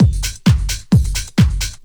Index of /90_sSampleCDs/Ueberschall - Techno Trance Essentials/02-29 DRUMLOOPS/TE06-09.LOOP-TRANCE/TE08.LOOP-TRANCE3